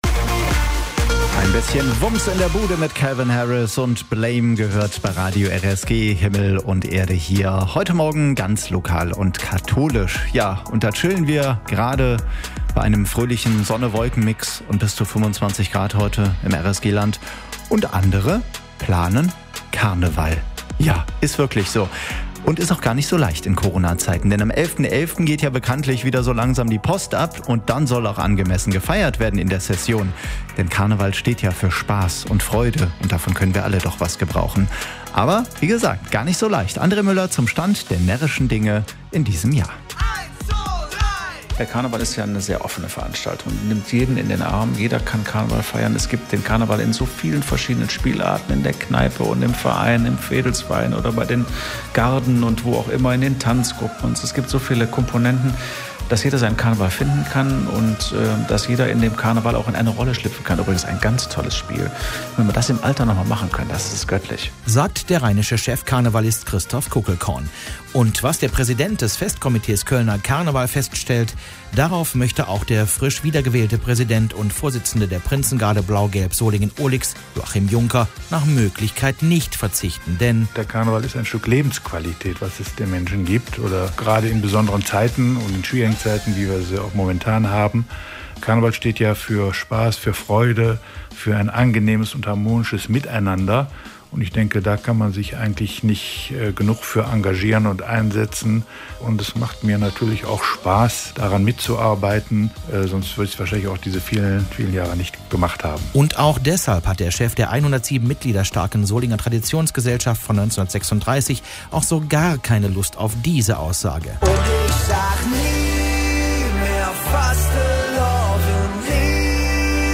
Karneval in Corna-Zeiten planen, gar nicht so leicht. Wir haben mit der Prinzengarde Blau-Gelb Solingen-Ohligs gesprochen.